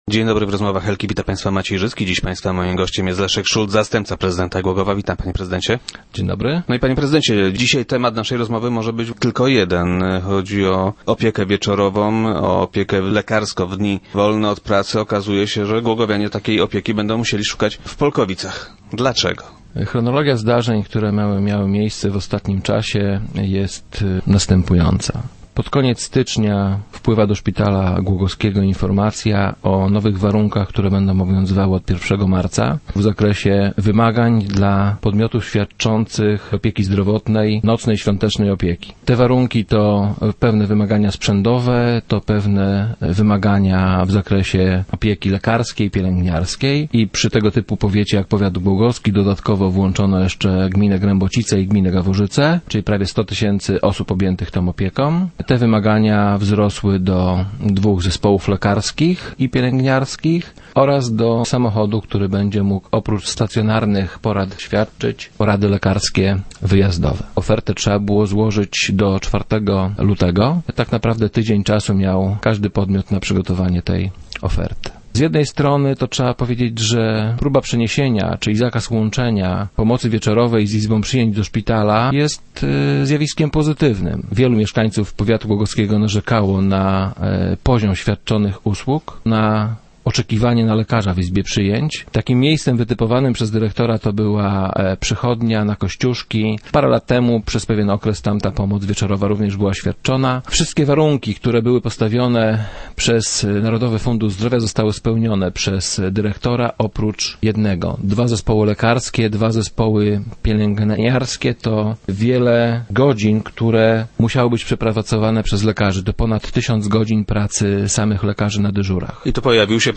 Od wczoraj głogowianie muszą szukać lekarskiej pomocy wieczorowej w Polkowicach. Zdaniem wiceprezydenta Leszka Szulca, władze miasta zrobiły wszystko co mogły, by te świadczenia realizowane były w Głogowie. Zastępca prezydenta był gościem dzisiejszych Rozmów Elki.